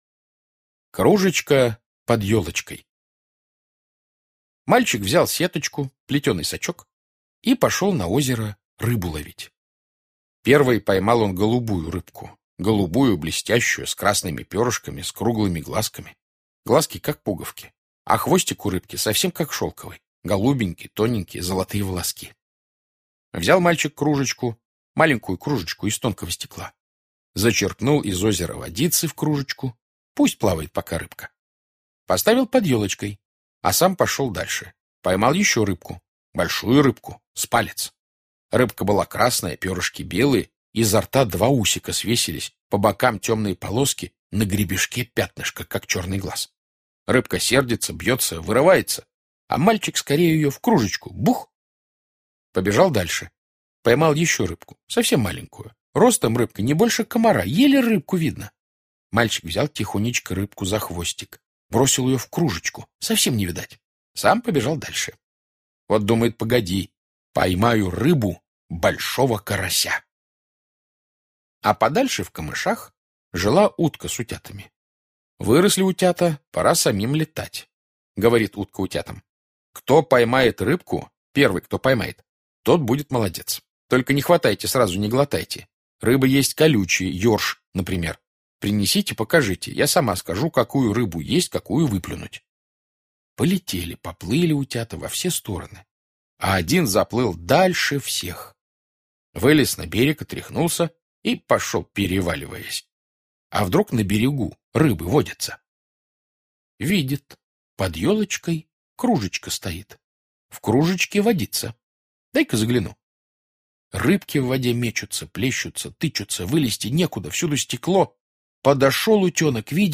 Кружечка под елочкой - аудио рассказ Житкова Б.С. Один мальчик ловил на озере маленьких рыбок и складывал в кружку с водой...